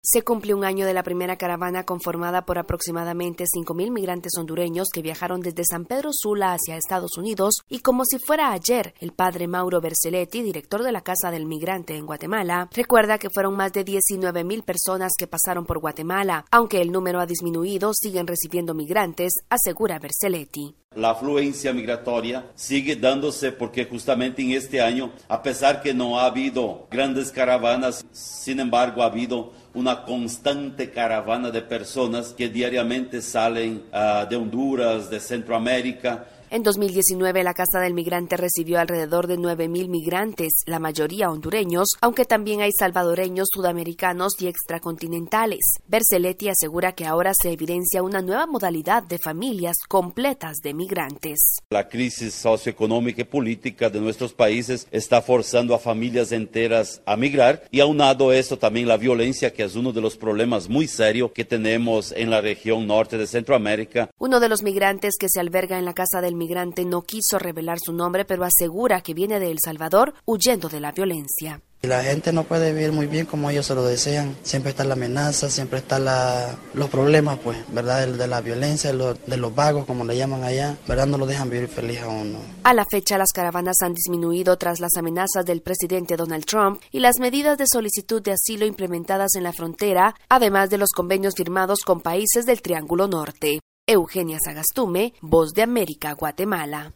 VOA: Informe de Guatemala